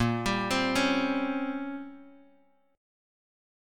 Bbm6add9 Chord
Listen to Bbm6add9 strummed